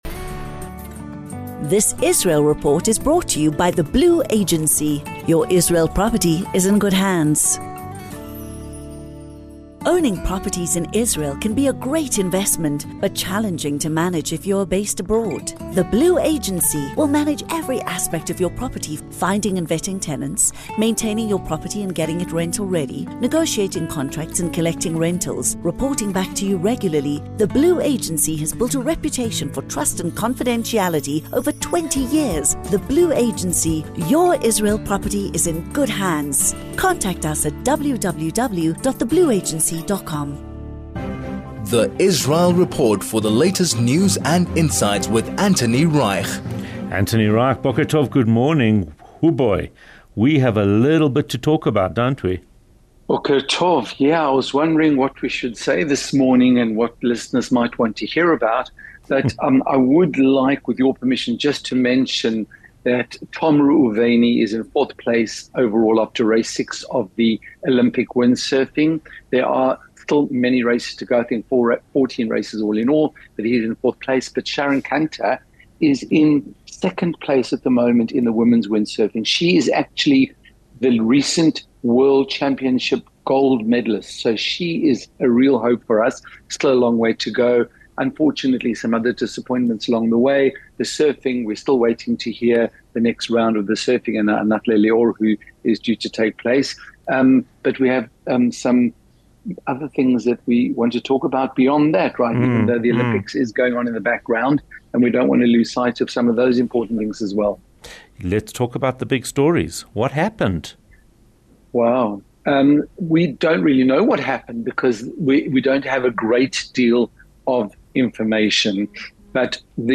Israel Report